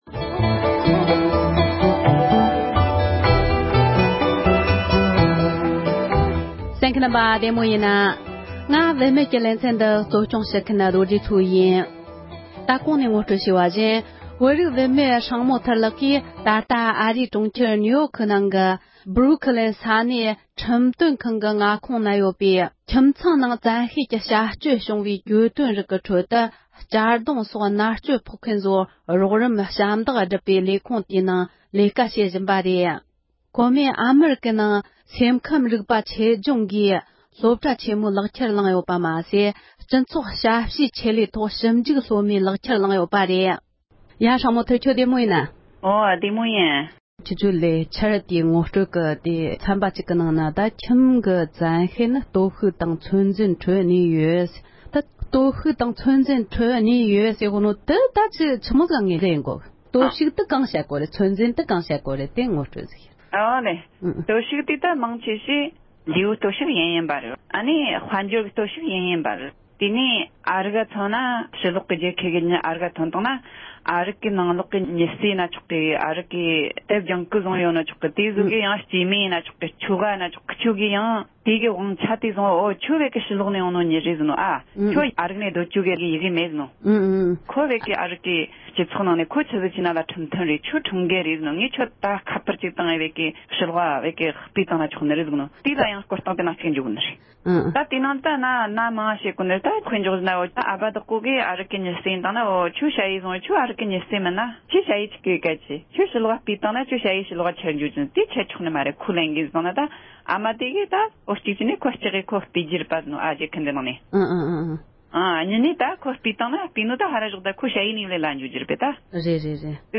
གླེང་མོལ་ཞུས་པའི་དུམ་བུ་གཉིས་པ་འདི་གསན་རོགས